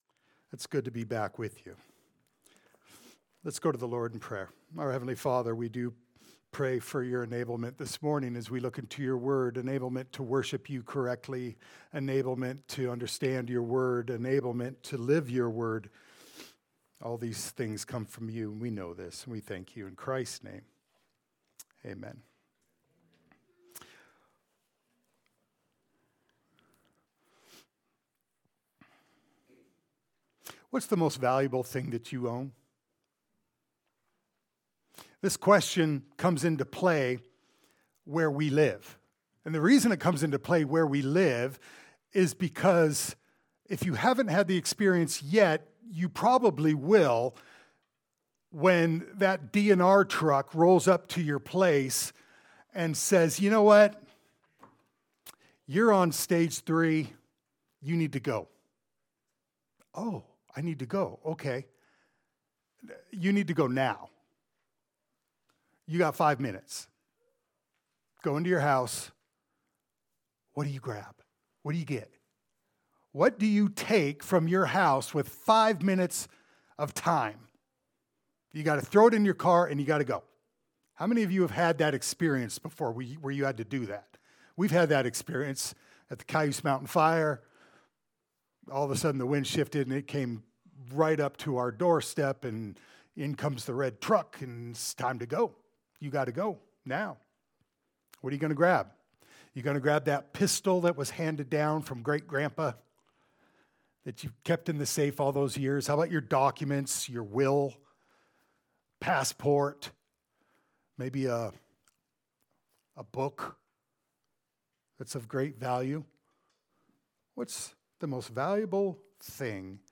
Topical Passage: James 1:13-17 Service Type: Sunday Service « “At the Feet of Jesus” “An Introduction to Titus